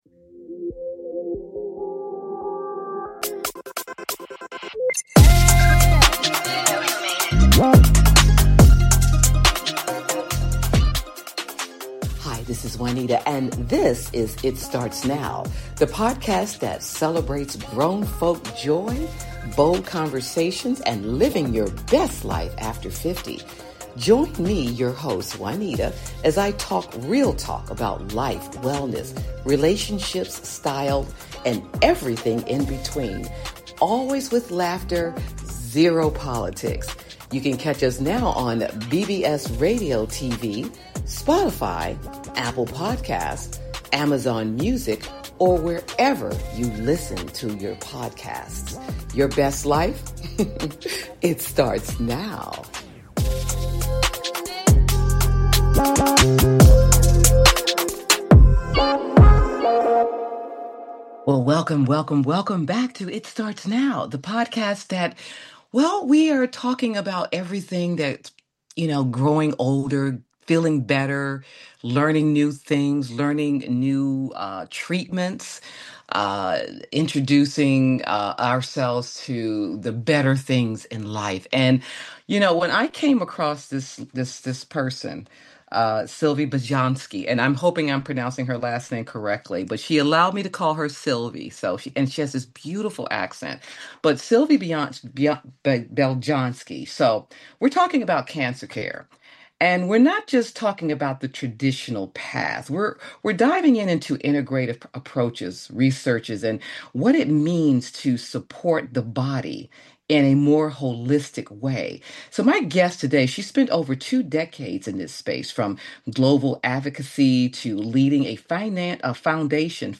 Talk Show Episode, Audio Podcast
Each episode offers a blend of uplifting stories, personal reflections, and candid conversations, with guests who are living boldly and aging out loud.